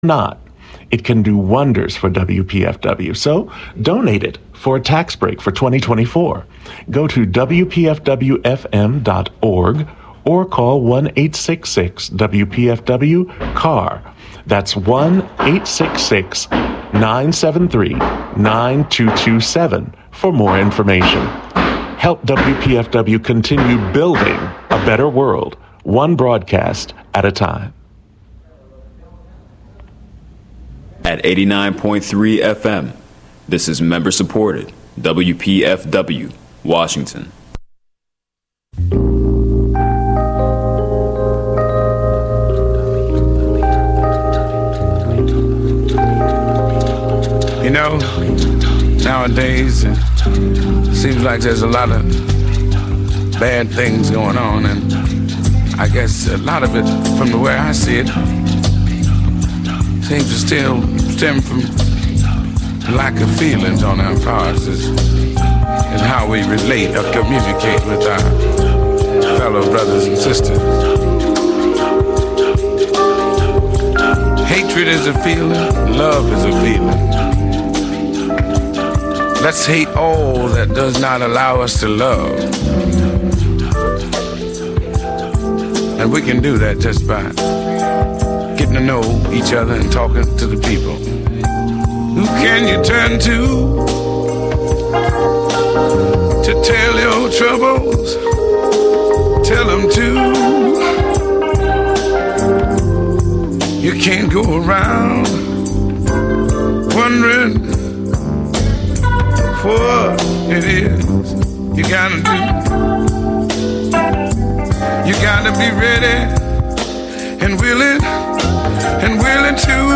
An open forum for debating local, global, social, entertainment, and political news.